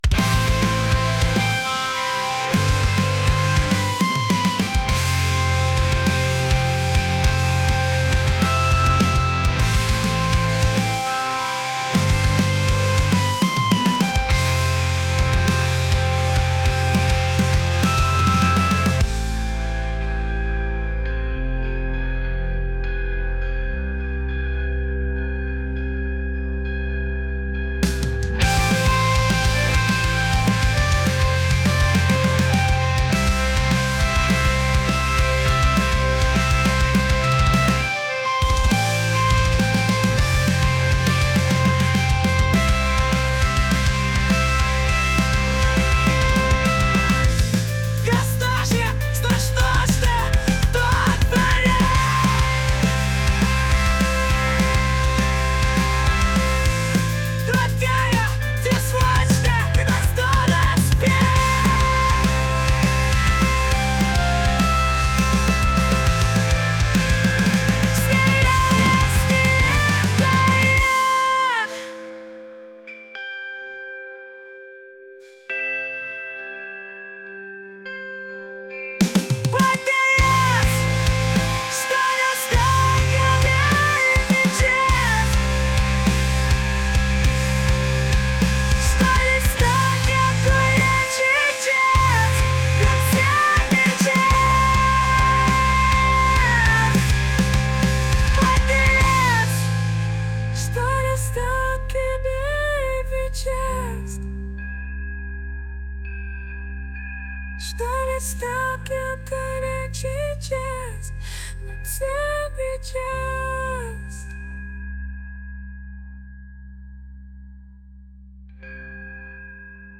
intense | heavy